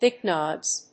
音節bi・con・vex 発音記号・読み方
/bὰɪkάnveks(米国英語)/